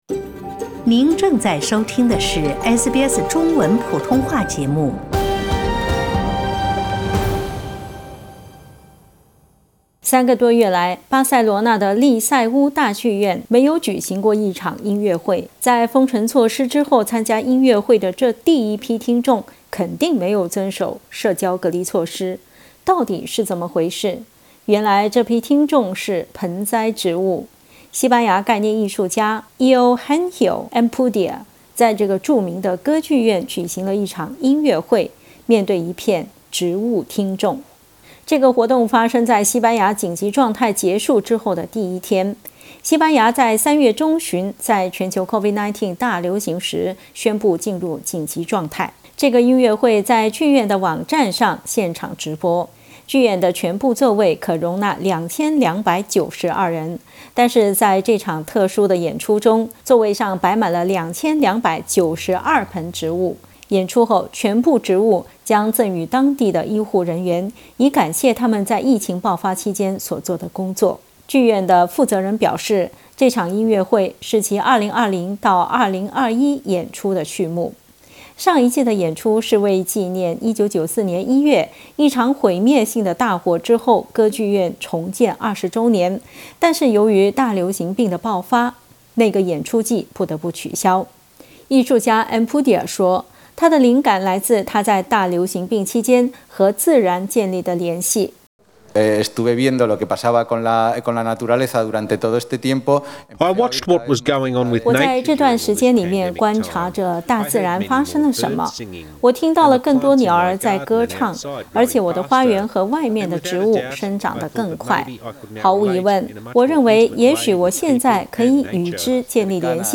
三个多月来，巴塞罗那的利塞乌大剧院（Liceu ）歌剧院没有举行过音乐会。在封城措施后参加音乐会的第一批听众完全没有遵守保持社交距离的规定。点击图片收听详细报道。